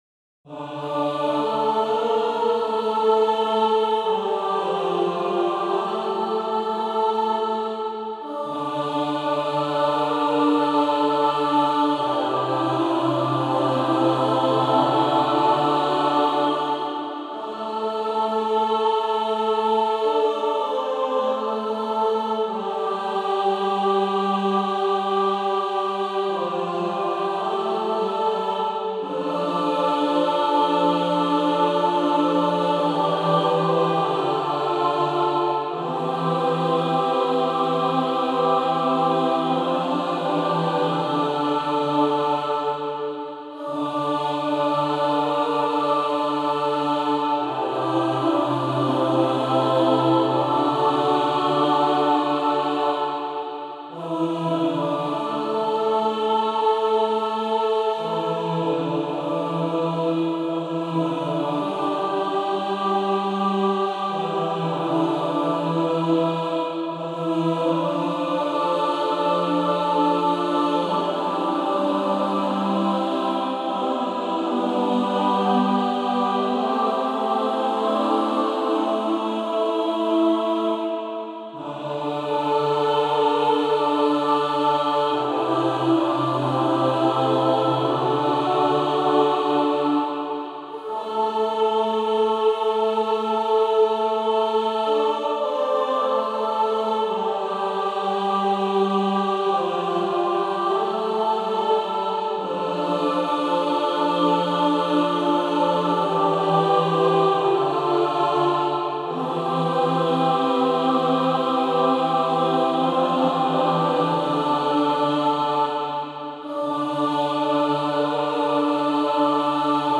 Vícehlasé žalmy na Bílou sobotu
Po 1. čtení Žalm 103(102) Veleb, duše má, Hospodina Petr Chaloupský SAABr PDF